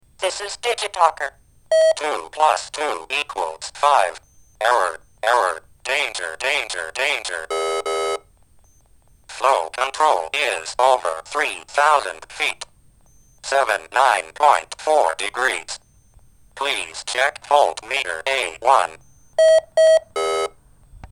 DigiTalker is a voice synthesis system National Semiconductor came out with in the late 70's as a solution to encorpirate clear speech into embedded appliances. The standard vocabulary kit came with 144 pre-loaded words that can be sequenced and arranged to make basic sentences. And, it sounds really good even by today's standards, but for one reason or another the product never really took off and National discontinued the DigiTalker line somewhere around 1990.
MP3 recording of the program output